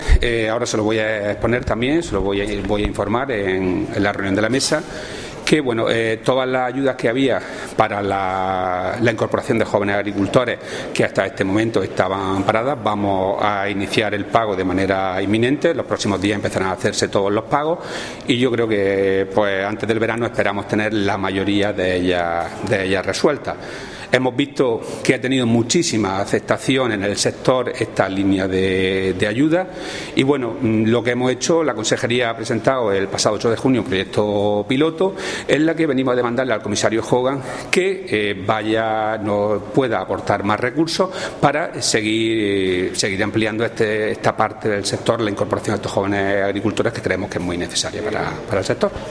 Declaraciones de Rodrigo Sánchez sobre el apoyo de la Junta de Andalucía a los jóvenes que apuestan por el sector agrario